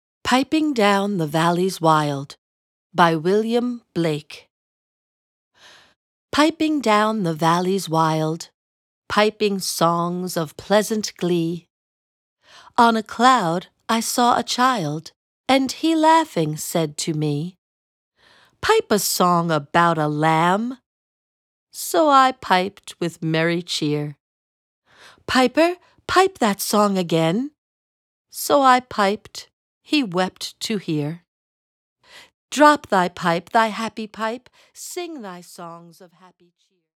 (Narrator)